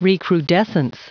Prononciation du mot recrudescence en anglais (fichier audio)
Prononciation du mot : recrudescence